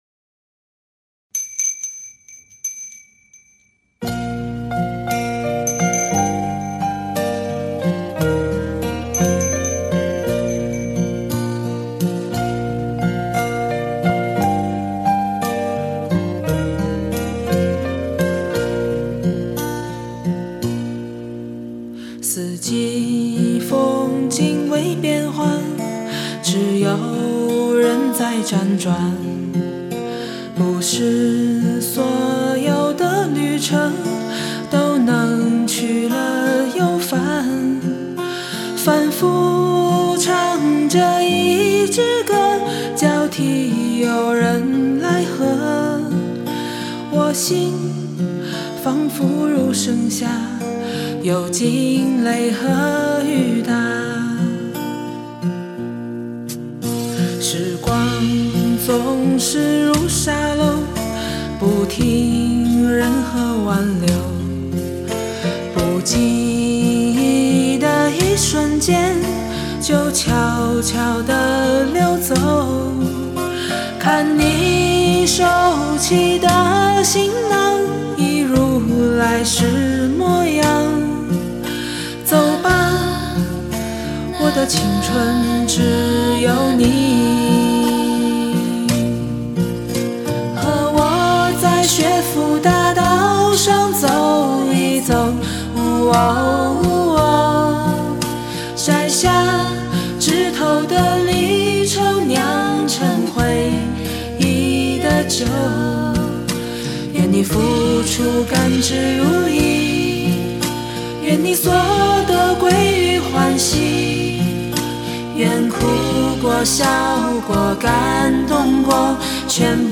和声